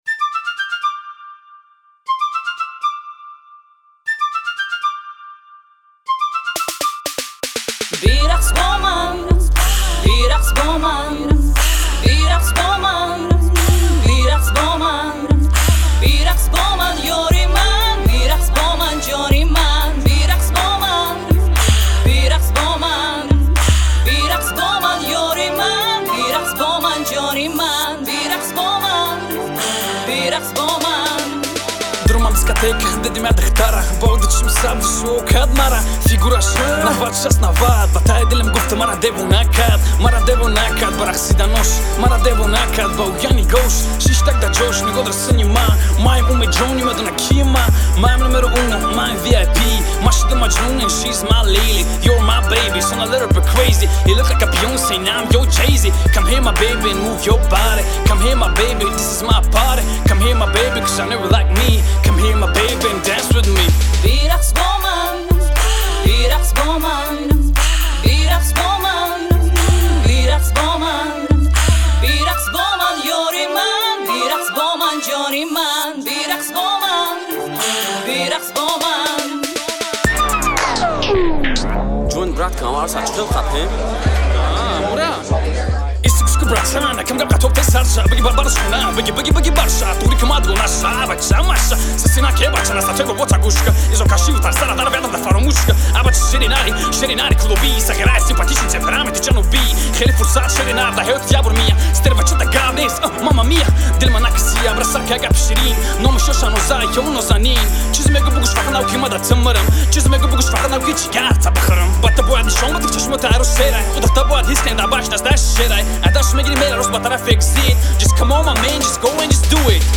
Категория: Тадж. Rap